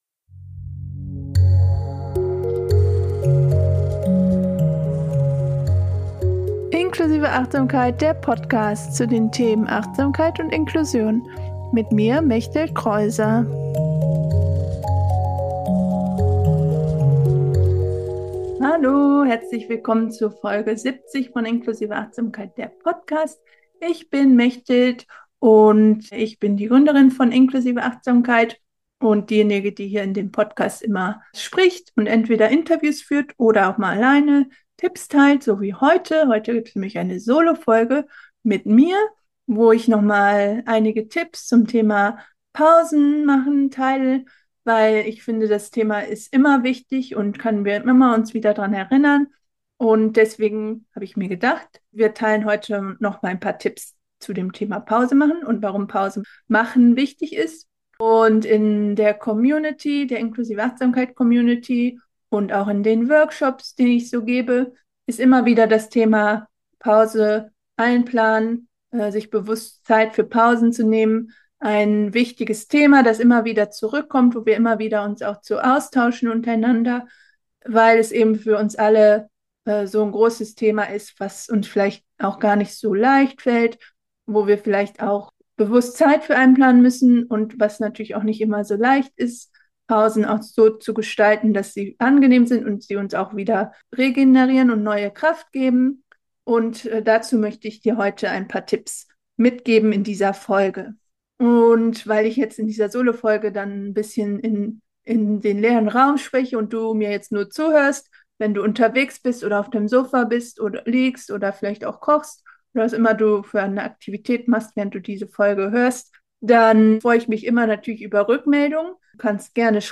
In dieser Solo-Folge spreche ich über das Thema Pausen machen. Es ist immer ein großes Thema in der Inklusive Achtsamkeit Community.